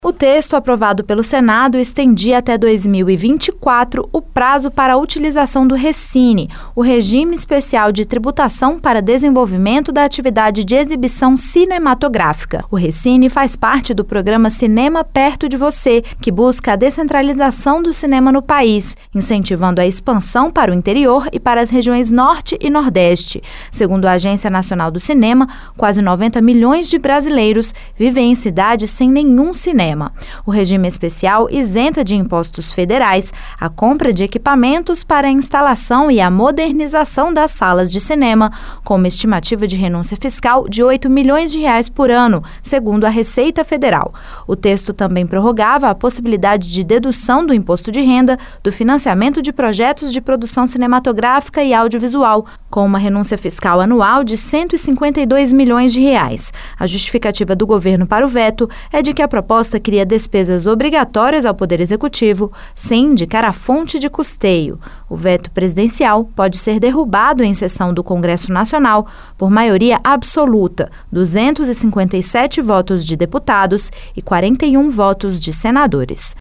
A justificativa é que a proposta cria despesas obrigatórias sem indicar a fonte de custeio. A reportagem